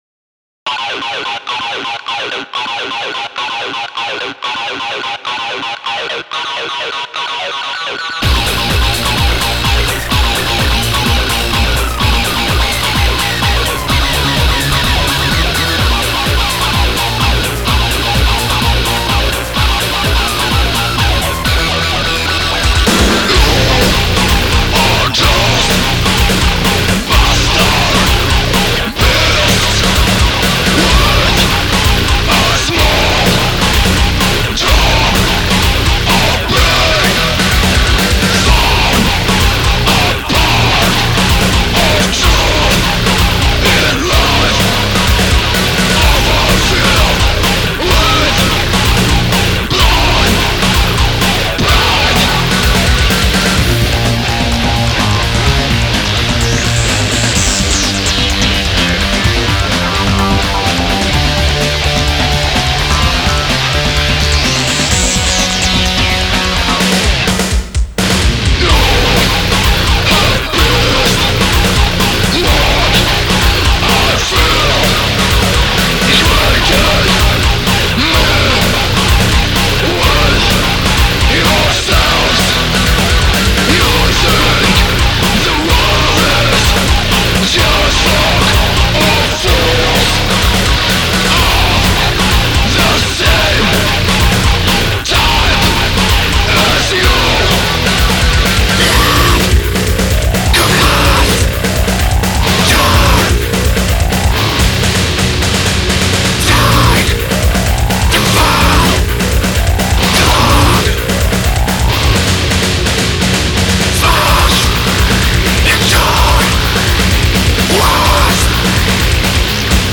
Cyber / Industrial Doom Metal